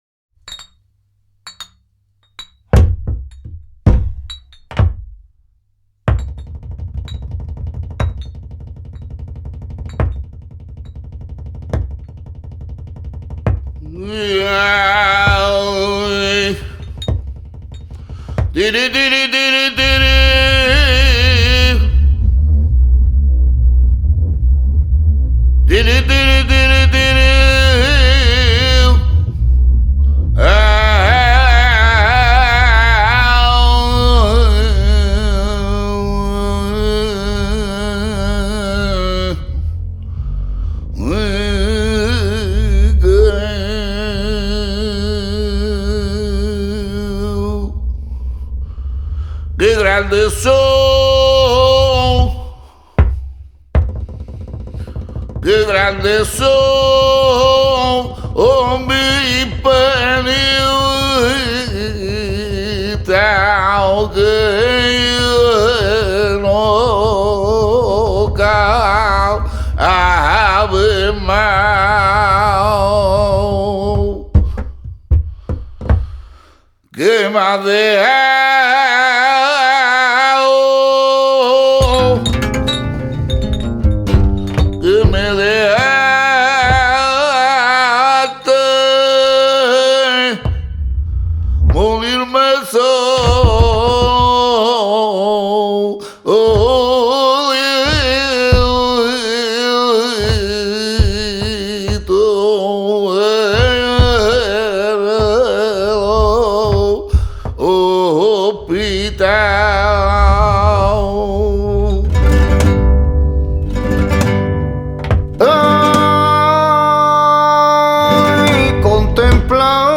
guitare
percussions